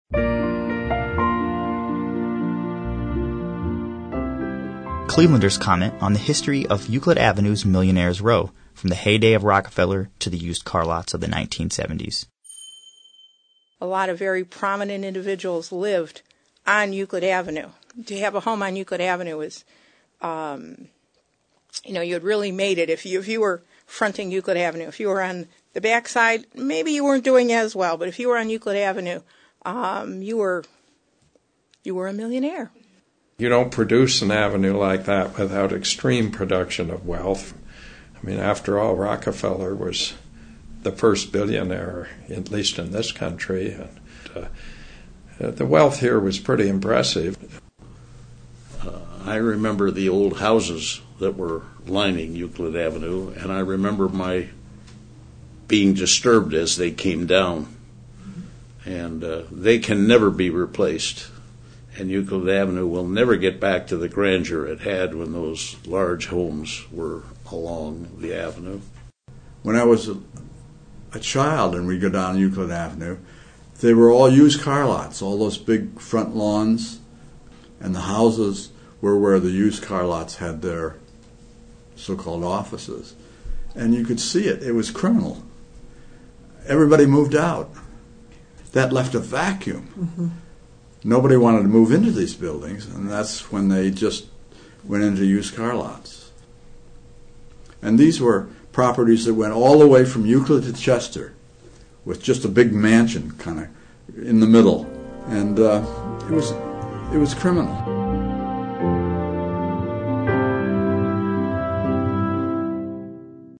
Clevelanders comment on the history of Euclid Avenue's Millionaires' Row from the heyday of Rockefeller to the used car lots of the 1970s | Source: Cleveland Regional Oral History Collection
Cleveland Regional Oral History Collection